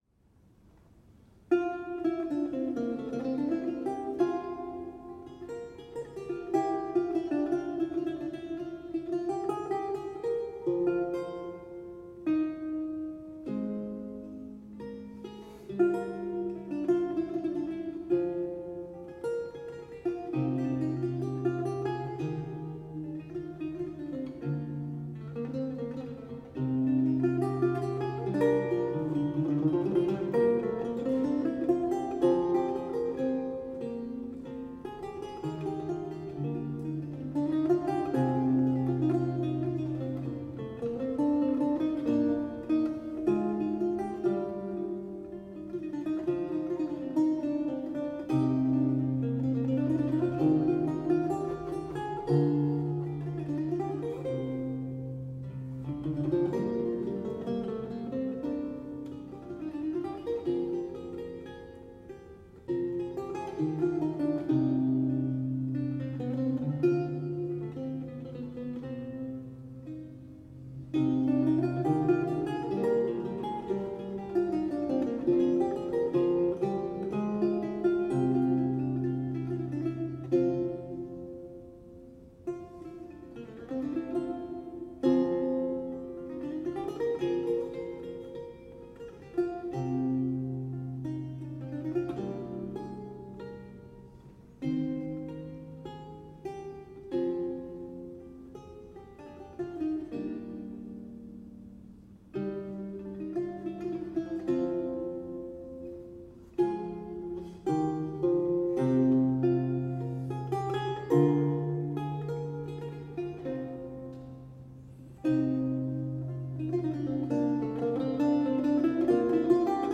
lute piece
a 16th century lute music piece originally notated in lute tablature